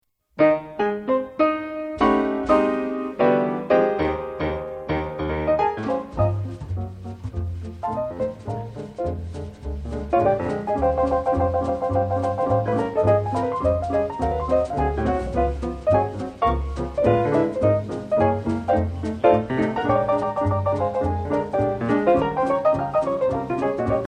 basse
batterie
NEW YORK City, The Columbia 30th street studio